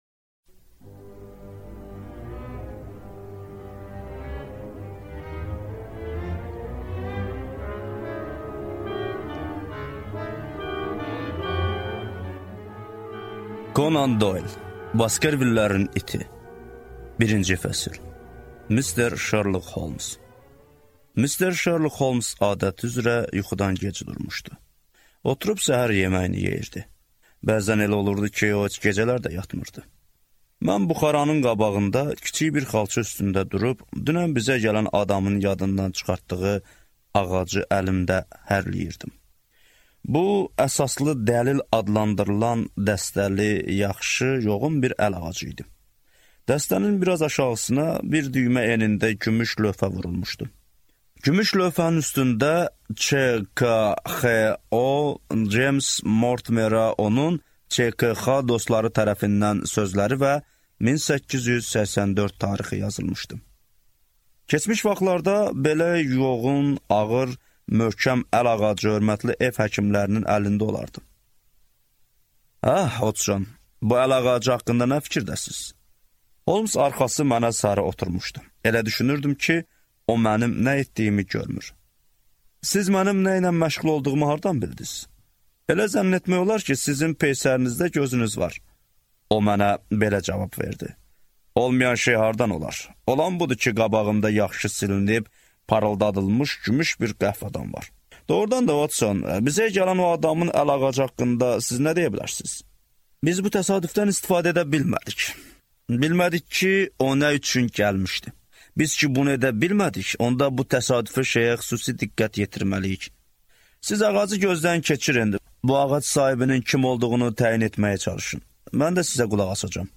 Аудиокнига Baskervillərin iti | Библиотека аудиокниг